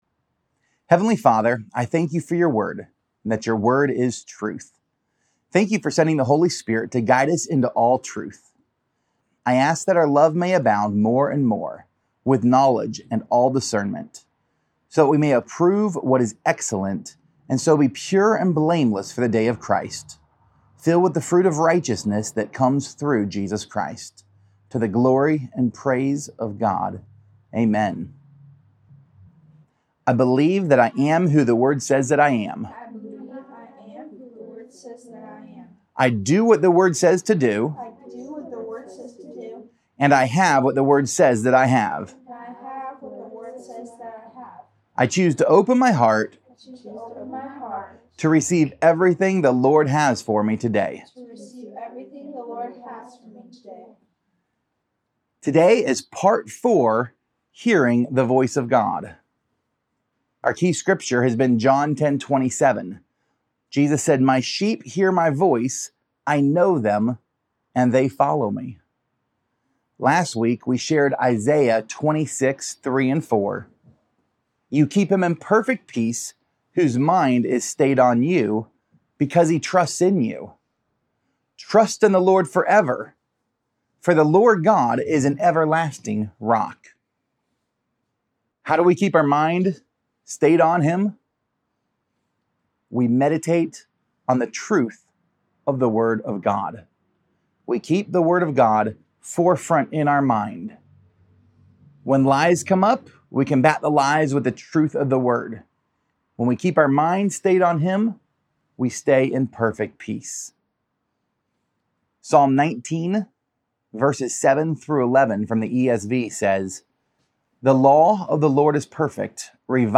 Pt 4: Being Led – Healing, Grace, and Gold | Sermon